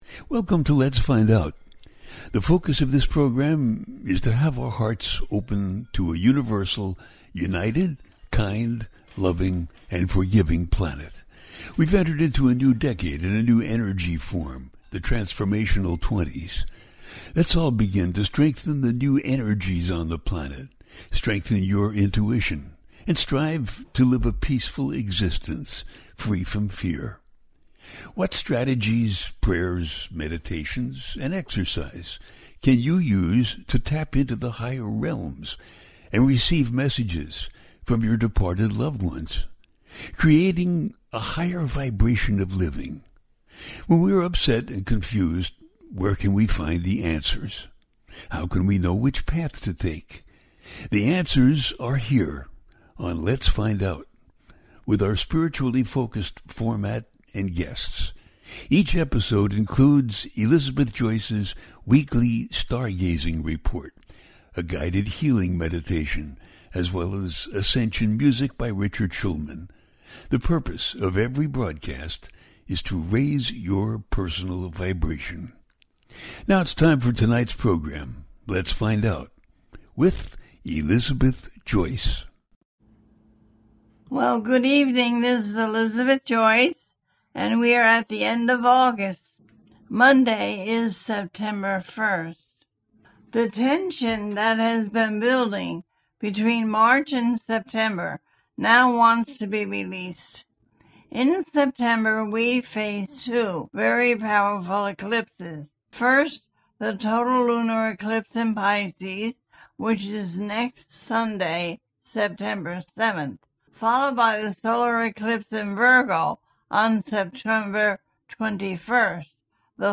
September 2025 - The Explosive Eclipse Month and its Impact - A teaching show